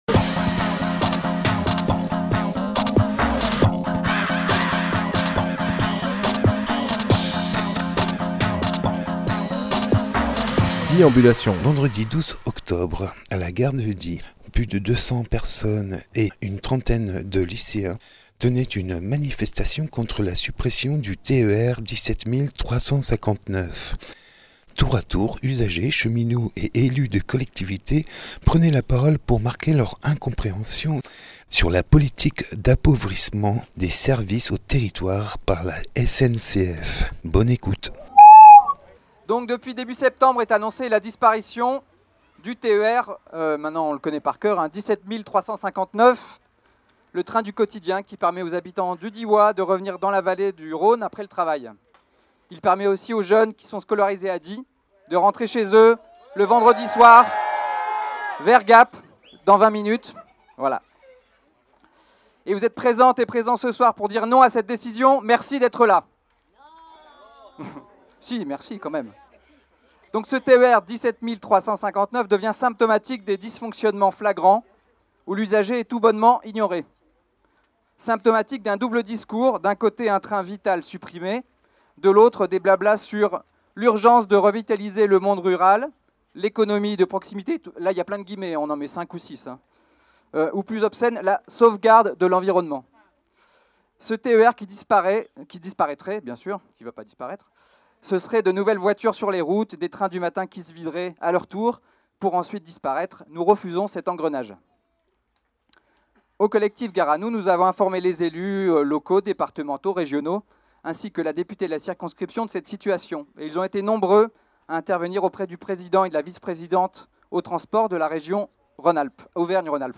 Emission - Reportages Gare à nous ! contre la suppression du TER 17 359 Publié le 14 octobre 2018 Partager sur…
Lieu : Gare sncf de Die